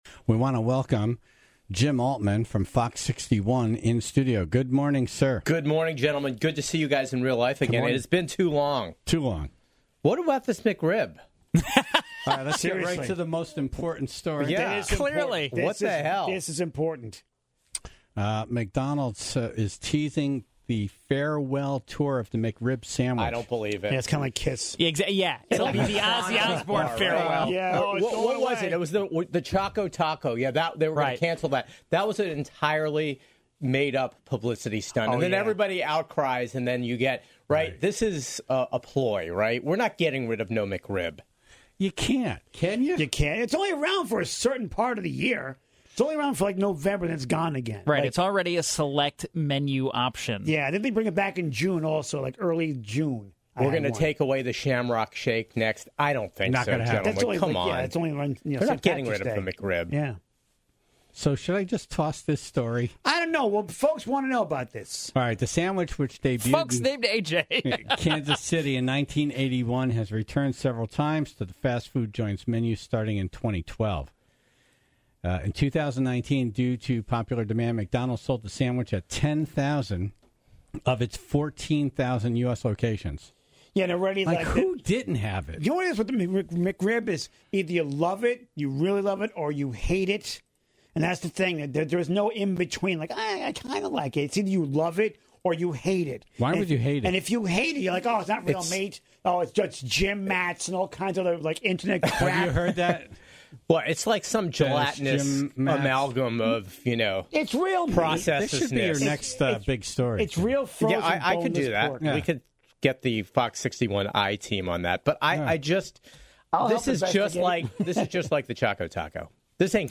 was in studio to talk about the best pizza places around.